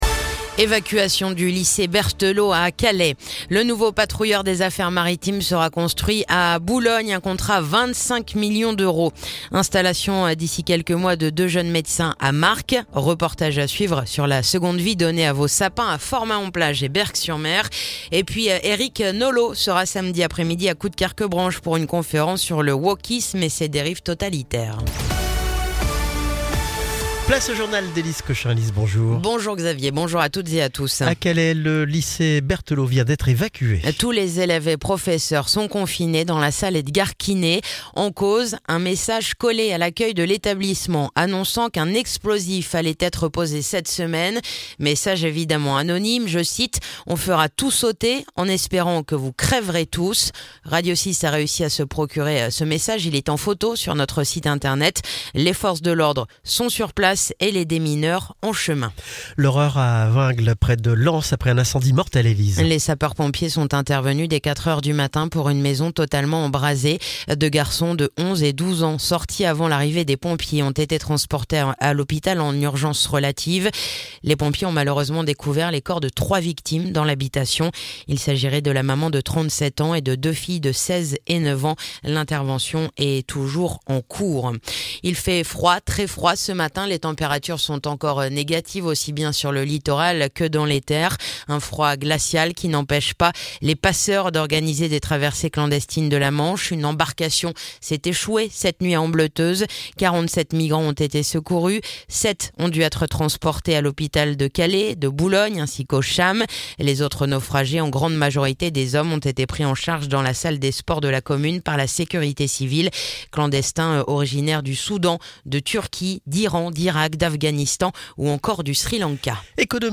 Le journal du lundi 13 janvier